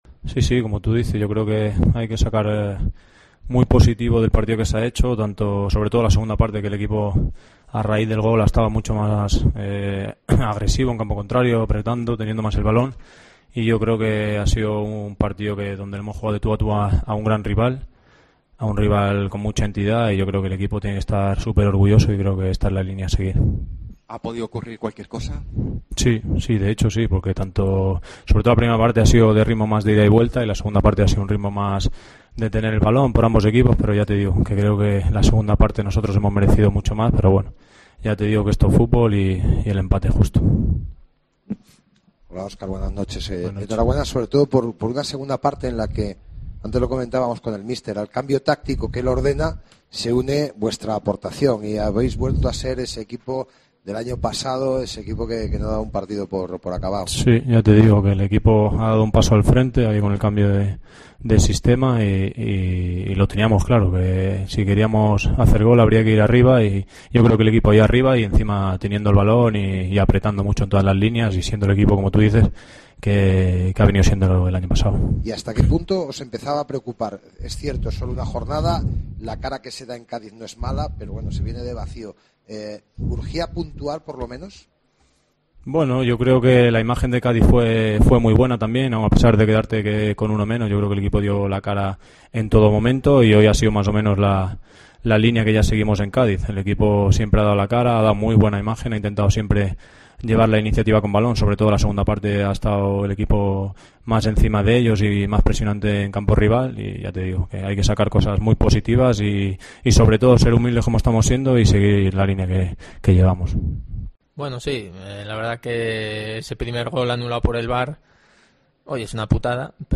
Declaraciones de los dos jugadores de la Deportiva Ponferradina tras el emapte 1-1 Zaragoza. Los bercianos suman su primer punto de la temporada